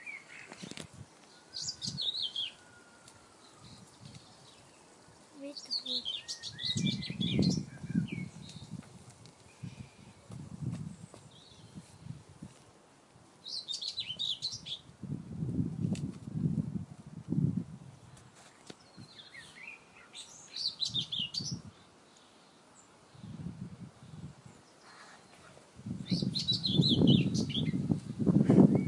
这段录音是在早上5点30分完成的，使用BP4025话筒和Oade改装的FR2le录音机。未经过滤，未加剪辑。
标签： 鸟鸣声 早上 春天 春天 dawnchorus 现场记录
声道立体声